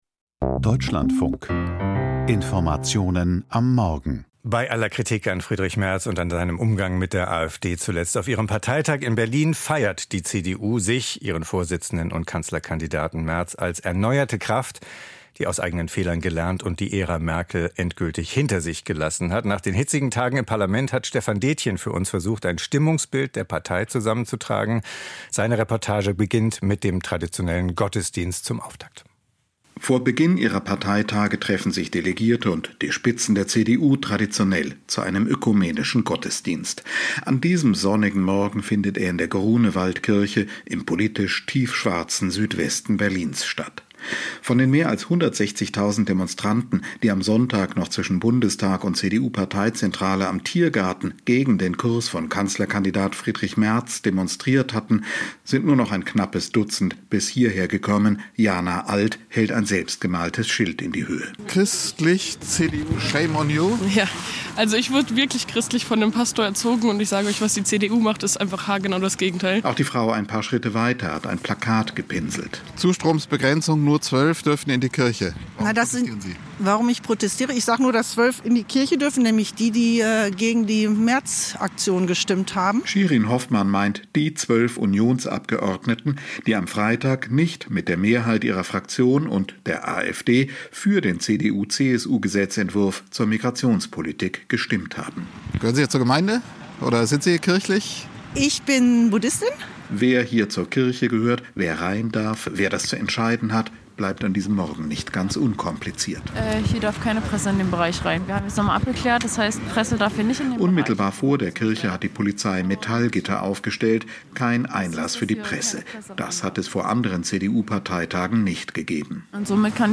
CDU auf Distanz zu den Kirchen - Reportage vom Parteitag